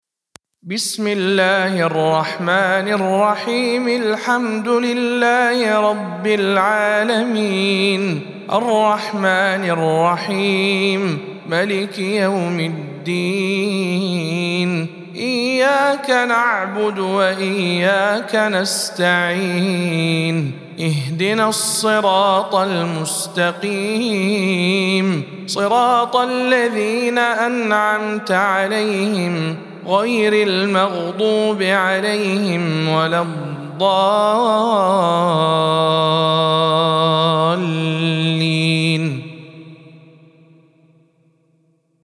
سورة الفاتحة - رواية هشام عن ابن عامر